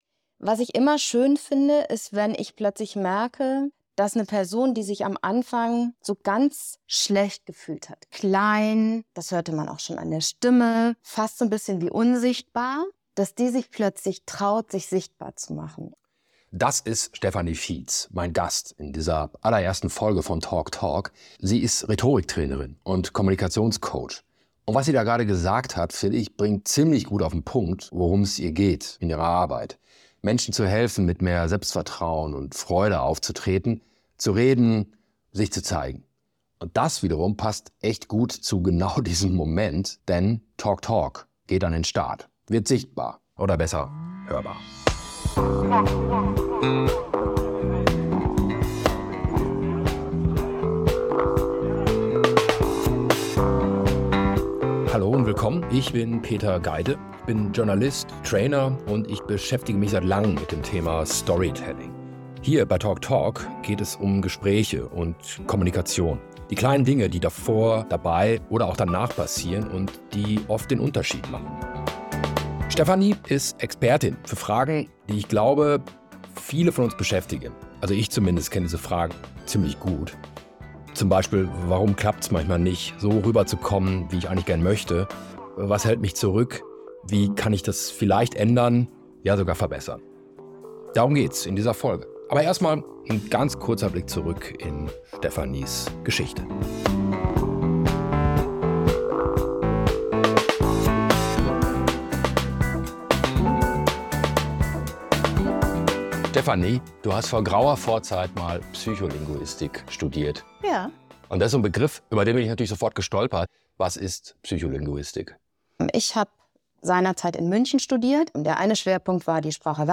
Doch schnell ging es um viel mehr: innere Blockaden, Stimme, Körpersprache, Prägung. Und darum, inwiefern gelingende Kommunikation mit Selbsterkenntnis zu tun hat. Ein Gespräch über das, was wir sagen, und das, was darunterliegt.